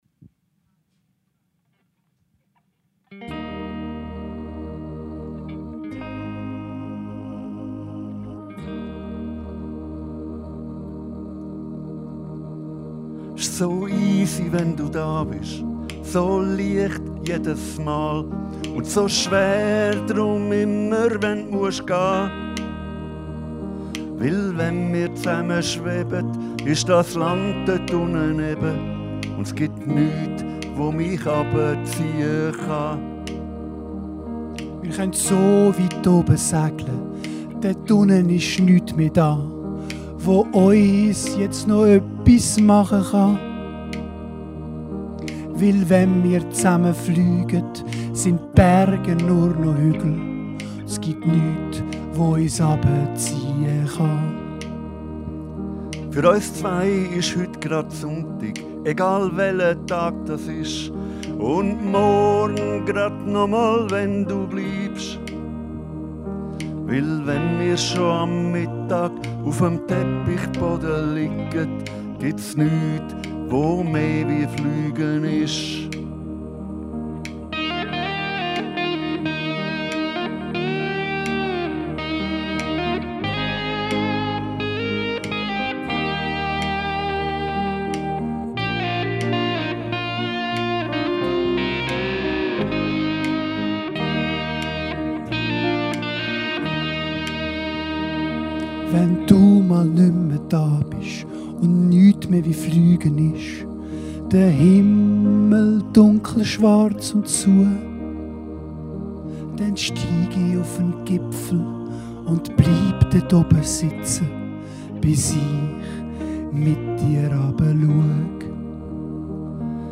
Unsere Interpretation